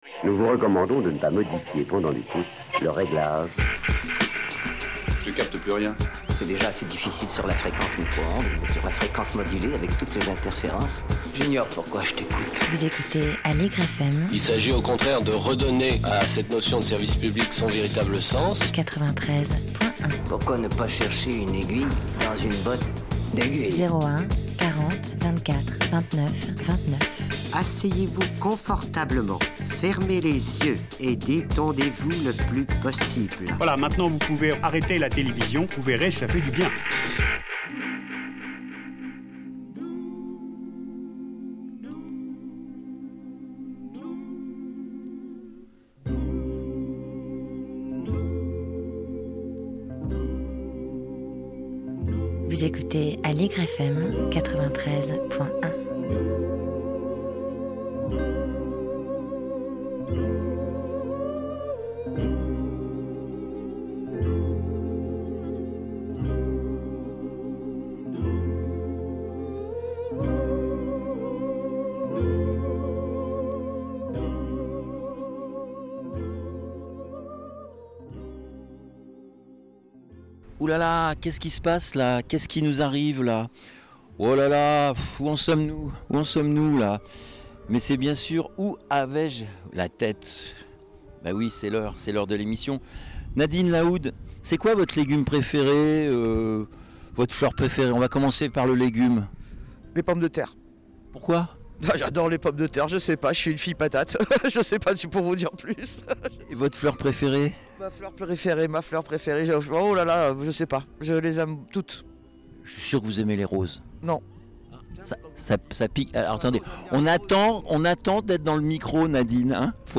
Visite guidée de la ferme urbaine gérée par l’association Veni verdi.